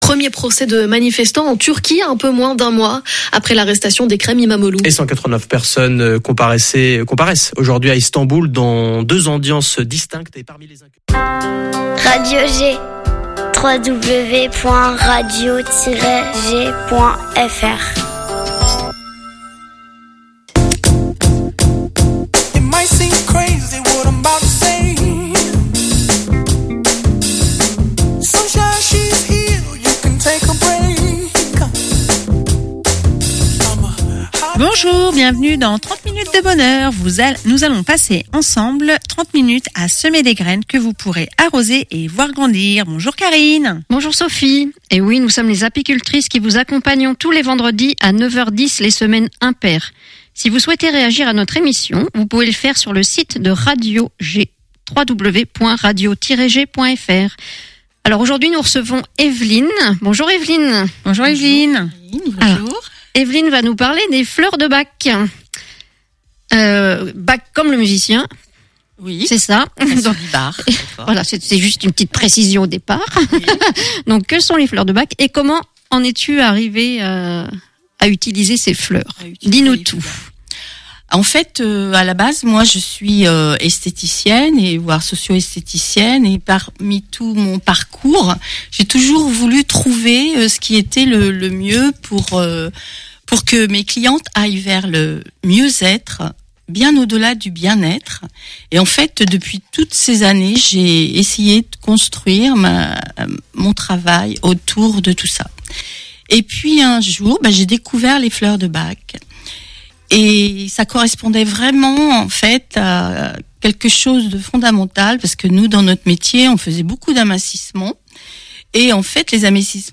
Venus d’ailleurs, c’est une série de conversations avec des actrices ou acteurs engagés dans la vie sportive angevine, et qui ont un jour quitté leur pays natal pour arriver jusqu’ici…